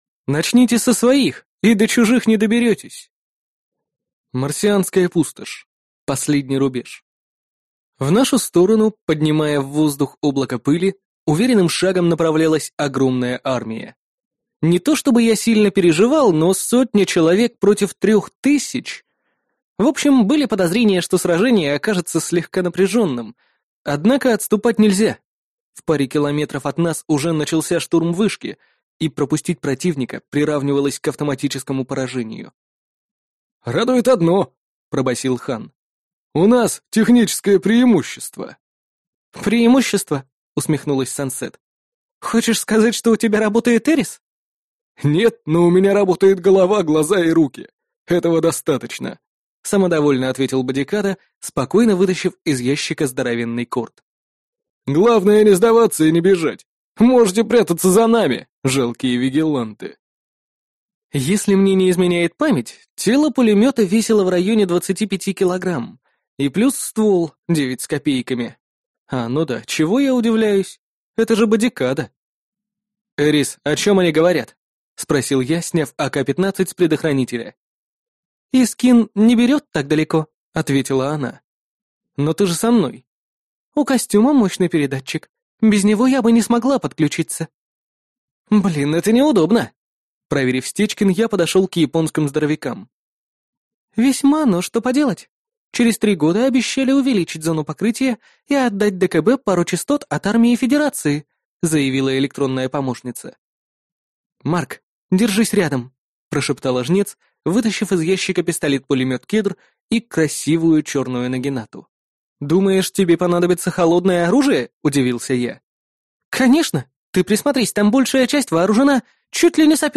Аудиокнига Дозорная башня | Библиотека аудиокниг
Прослушать и бесплатно скачать фрагмент аудиокниги